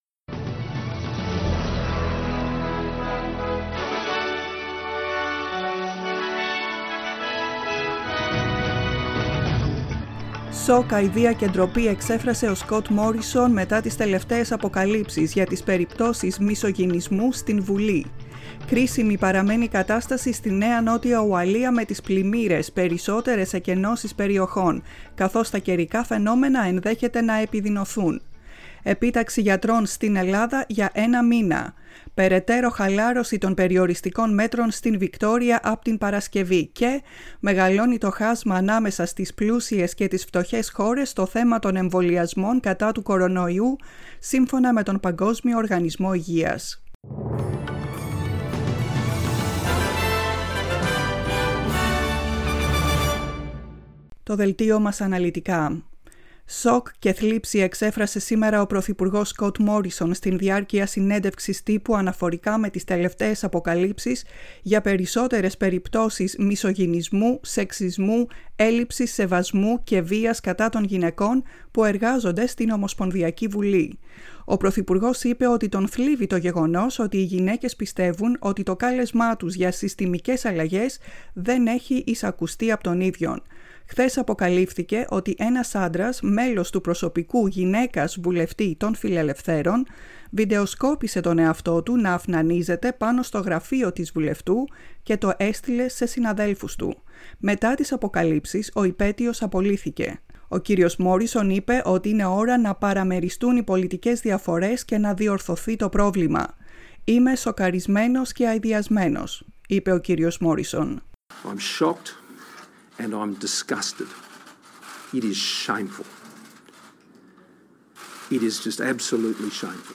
The main bulletin of the day